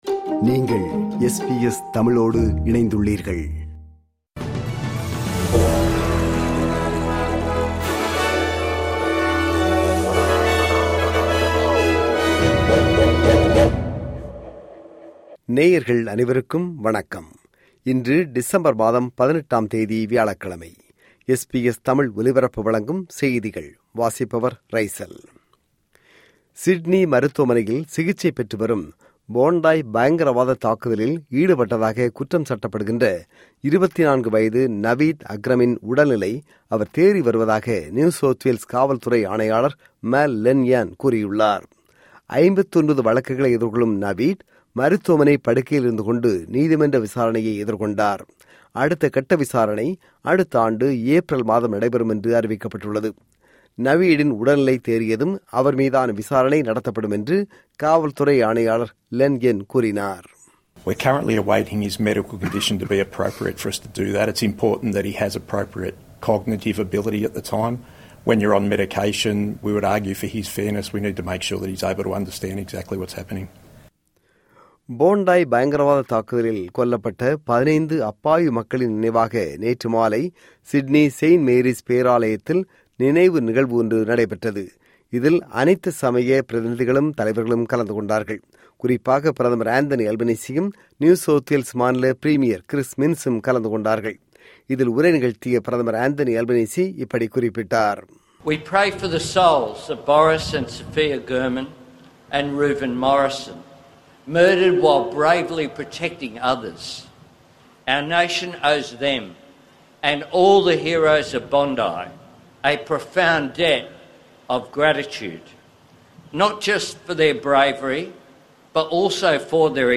SBS தமிழ் ஒலிபரப்பின் இன்றைய (வியாழக்கிழமை 18/12/2025) செய்திகள்.